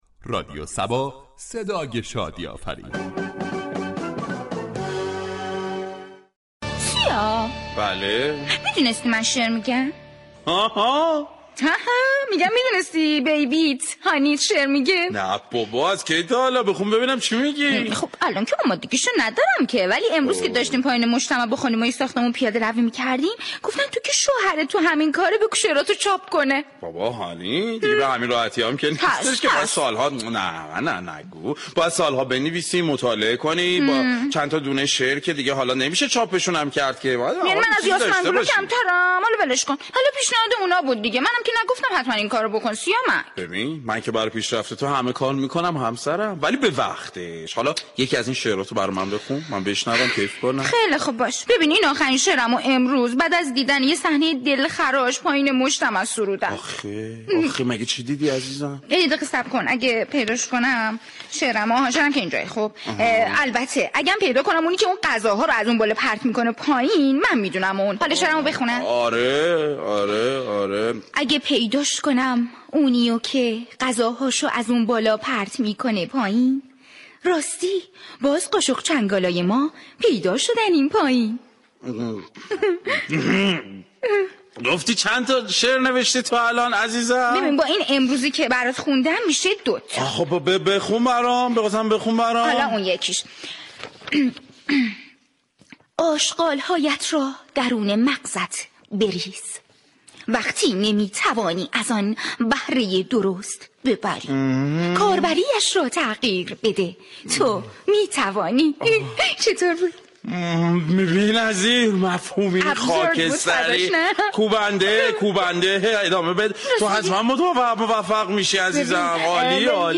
شهر فرنگ در بخش نمایشی با بیان طنز به موضوع "اهمیت توجه و تشویق همسران "پرداخته است ،در ادامه شنونده این بخش باشید.